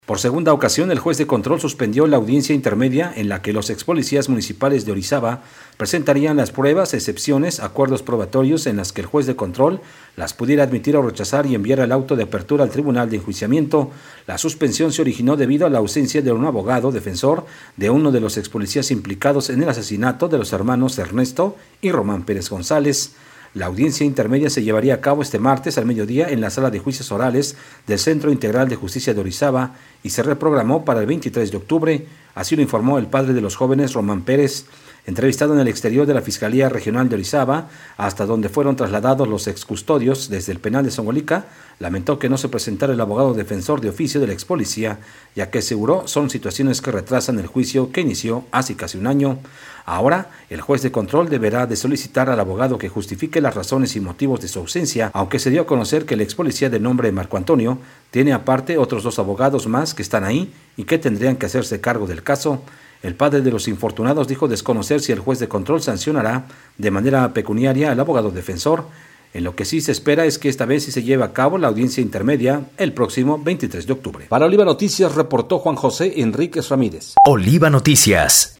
Oliva Radio Noticias